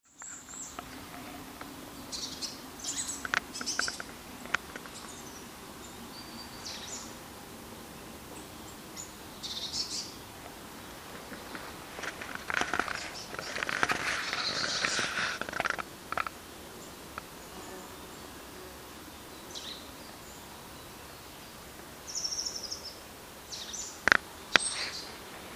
Seznamte se: SÝKORA MODŘINKA